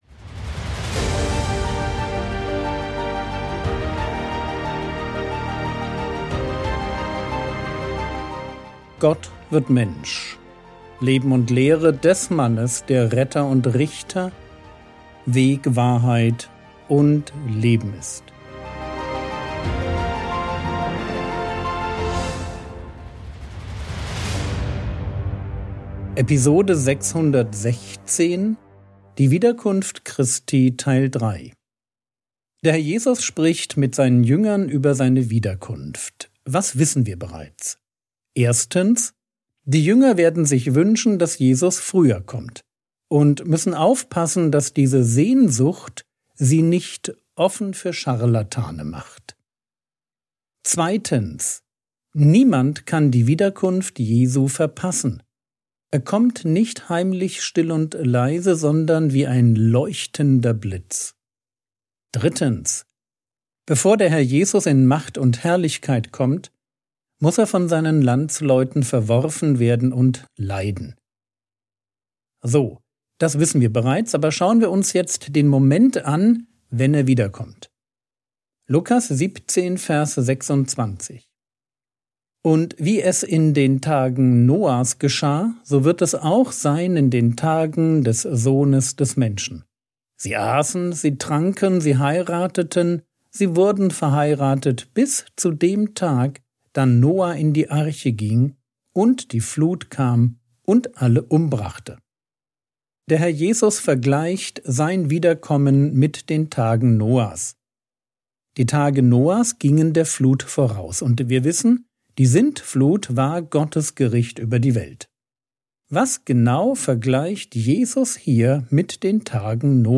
Episode 616 | Jesu Leben und Lehre ~ Frogwords Mini-Predigt Podcast